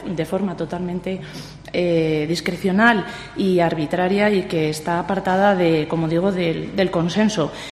Así la manifestaba en el Pleno de la ciudad el 7 de julio, al considerar que no se cumplía el criterio de proporcionalidad.